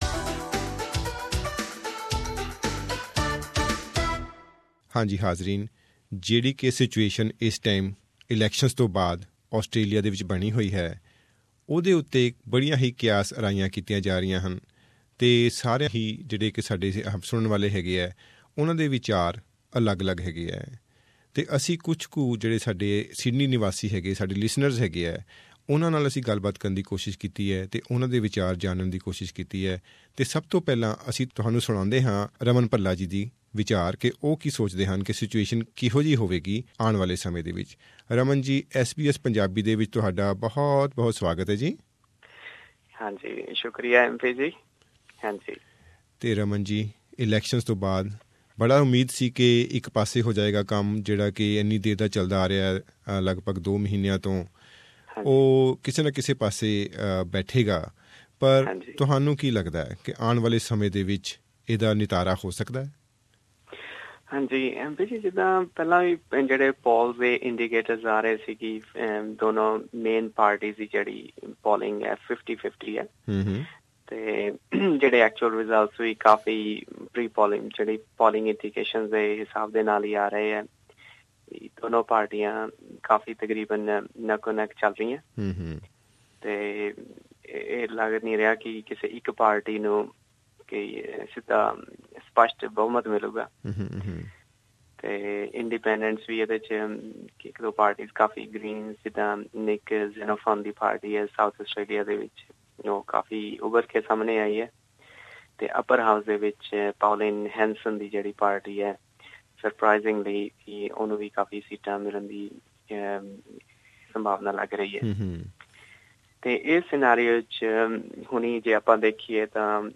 WE have spoken to some of our listeners in Sydney and tried to get their views about which party may claim to form the government, what challenges they may face, the current leadership challenges in Liberal party and how independents can affect the future of the government.